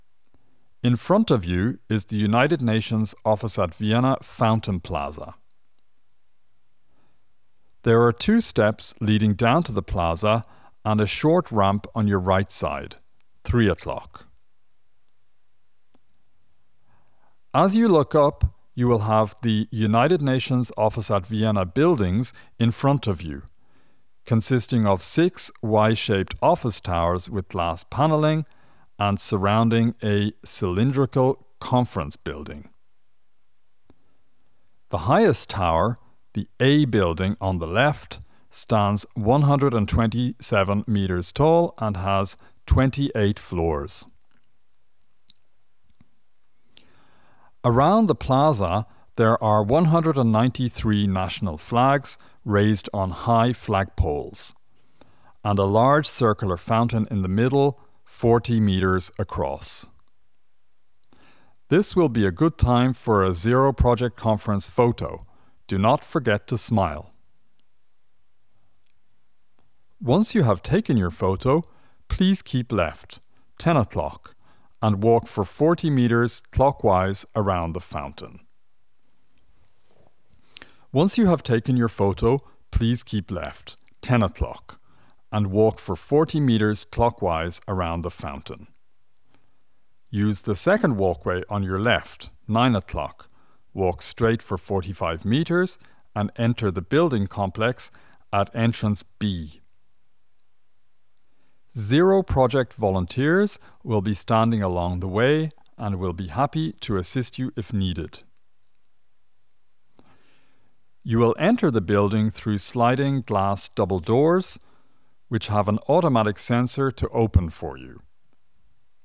Audio Guides for orientation